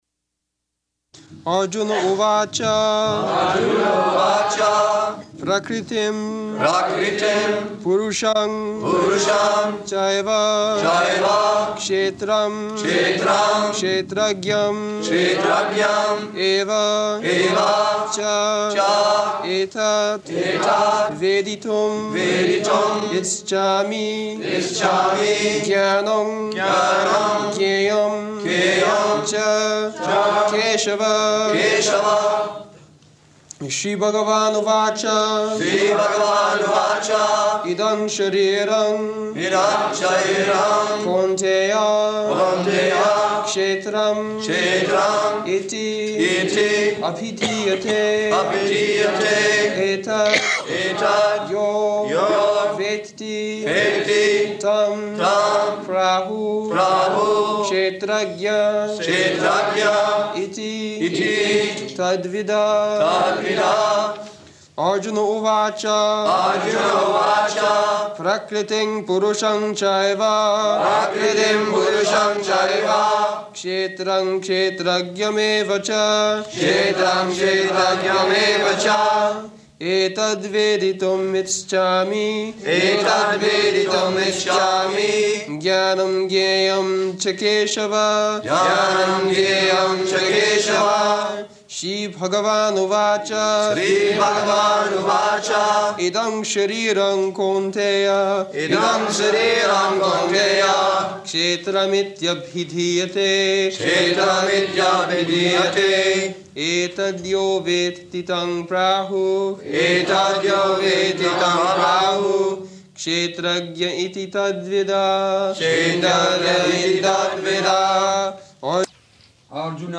Location: Paris
[Prabhupāda and devotees repeat]